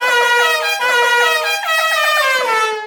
Play, download and share Ed Edd'n Eddy Horn original sound button!!!!
ed-eddn-eddy-horn.mp3